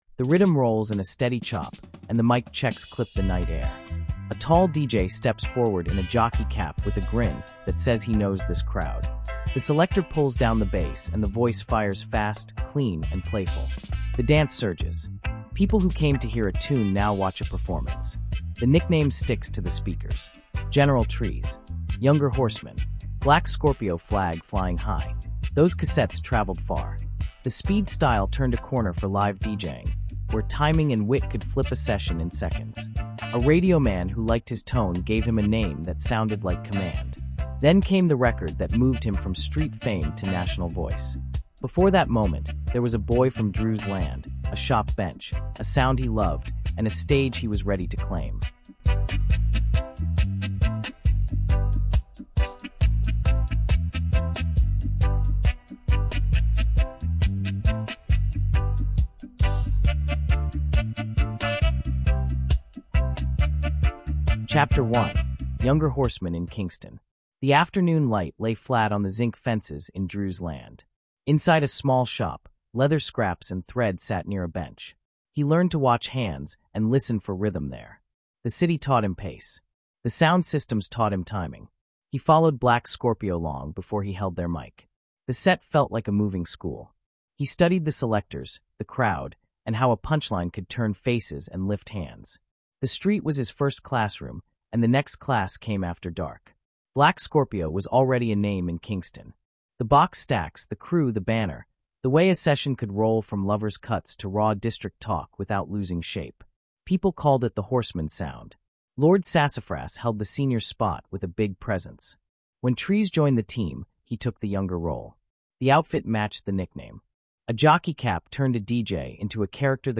General Trees: Fast-Style Don of Black Scorpio | Dancehall History Documentary
A clear, human story of a Kingston deejay who turned fast style into a language, carried daily life into hooks, and earned elder respect onstage and off. This is dancehall history told with care—sound system culture, island culture, and caribbean music in plain voice, built like a documentary with lived detail and clean pacing.